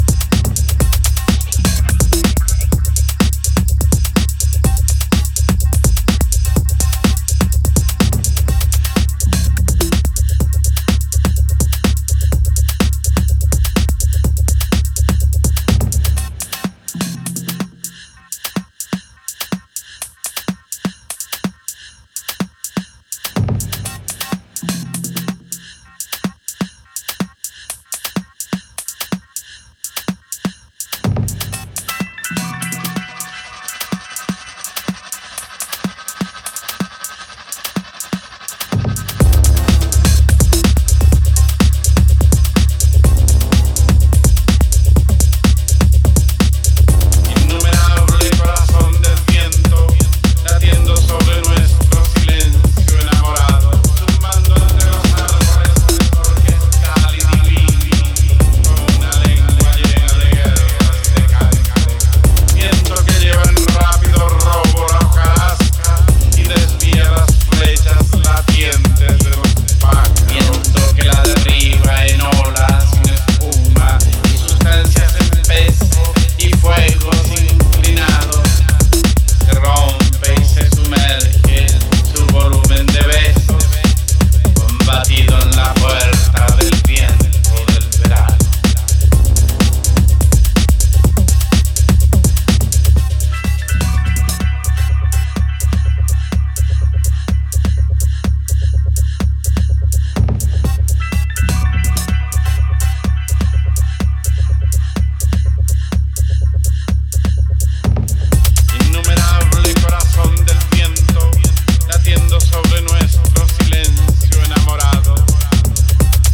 a ten-minute drift of slow-burning minimalism
elastic modular textures and patient rhythmic detail